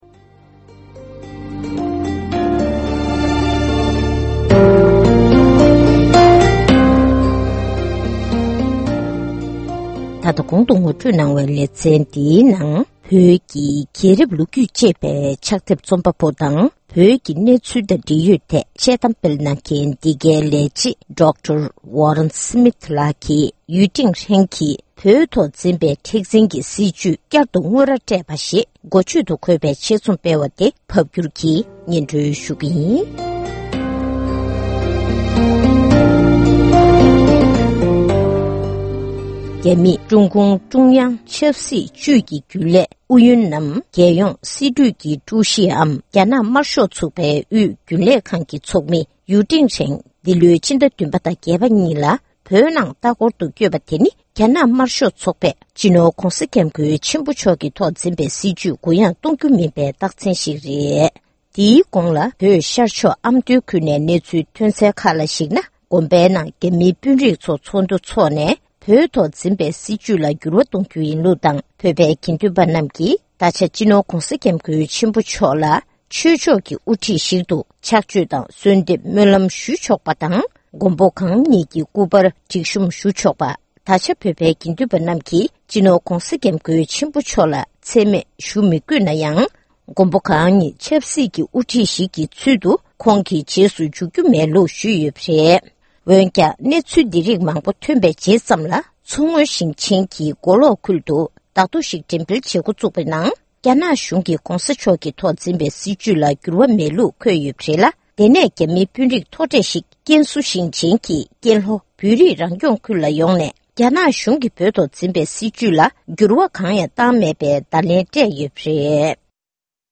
སྒྲ་ལྡན་གསར་འགྱུར། སྒྲ་ཕབ་ལེན།
བོད་སྐད་ཐོག་ཕབ་བསྒྱུར་གྱིས་སྙན་སྒྲོན་ཞུས་པར་གསན་རོགས༎